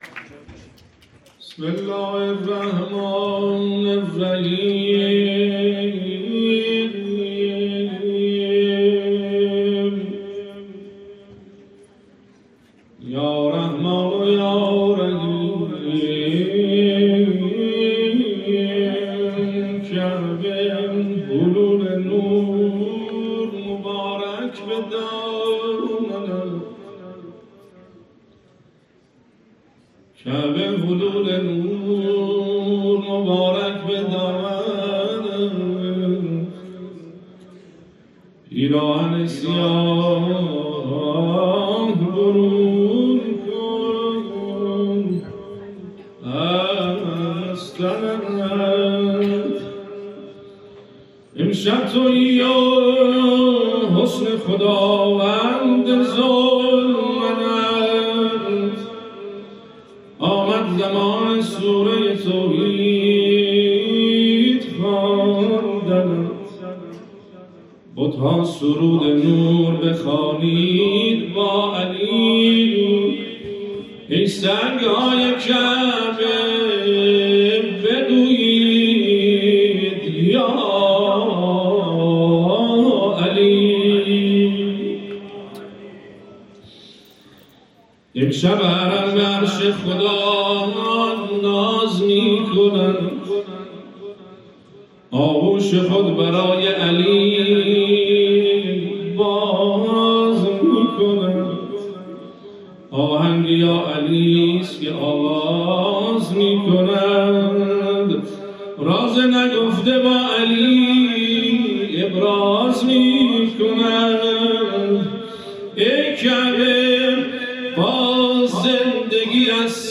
به گزارش خبرنگار فرهنگی خبرگزاری تسنیم، محمدمهدی طهرانچی، رئیس دانشگاه آزاد اسلامی در آیین افتتاحیه چهارمین جشنواره فرهنگی، علمی و هنری امامت و مهدویت که در جوار مضجع شریف امام رضا علیه‌السلام و در مجتمع شهدای سلامت برگزار شد، سخنرانی کرد.